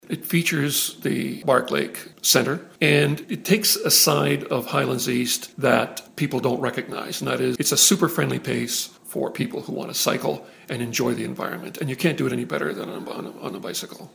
Cec Ryall is the councillor for the ward and says the race is a great opportunity to showcase the Highlands East scenery to visitors.